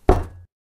land_wood.ogg